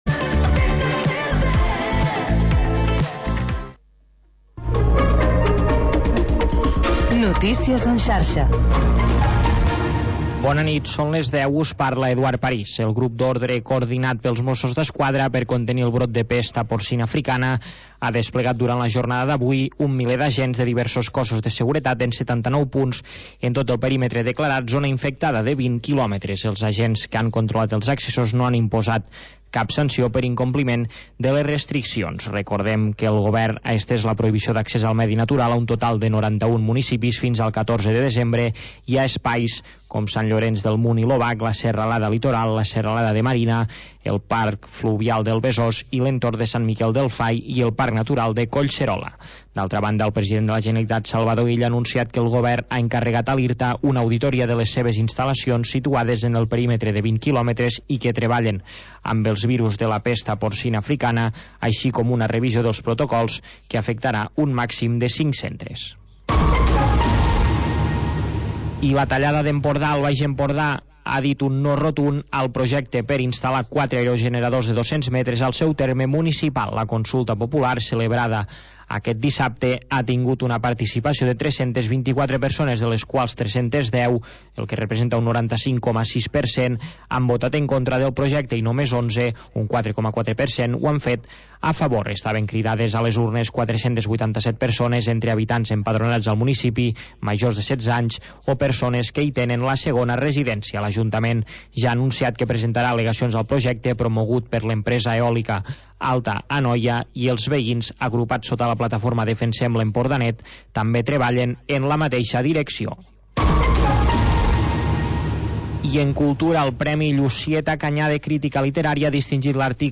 Selecció musical de Dj.